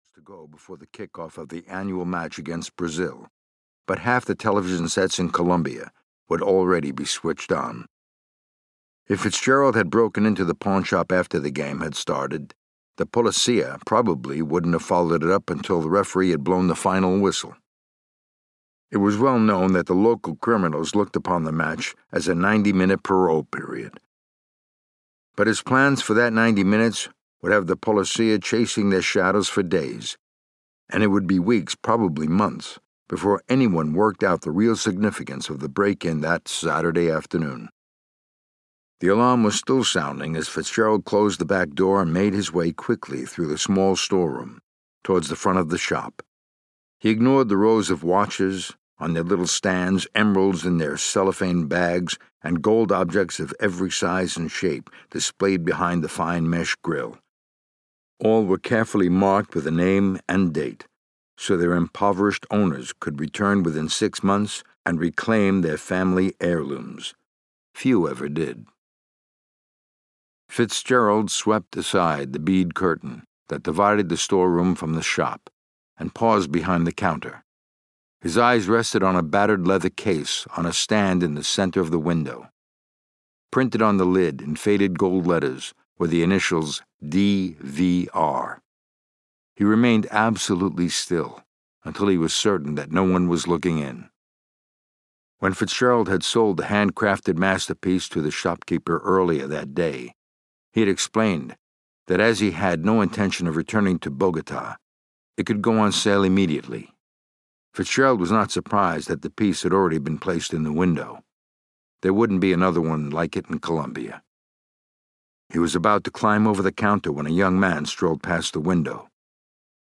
The Eleventh Commandment (EN) audiokniha
Ukázka z knihy
• InterpretMichael Brandon